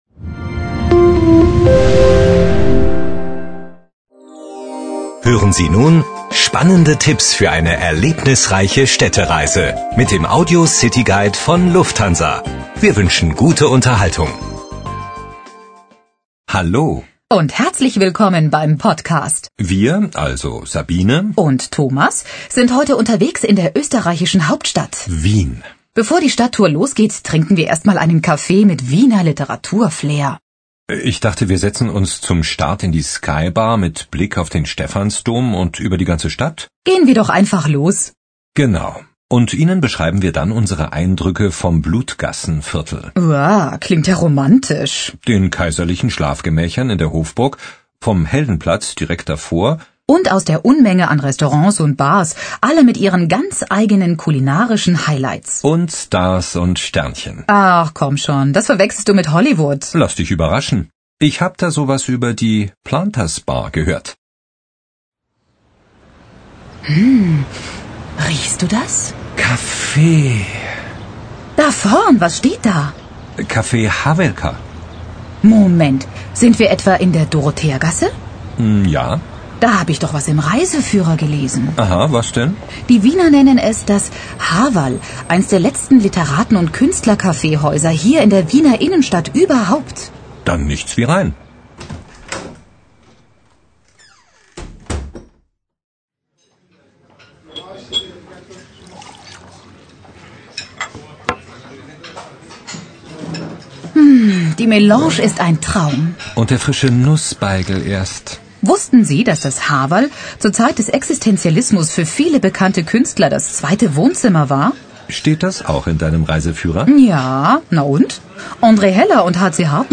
Lufthansa - Audio Guide (mp3, 3,2 MB)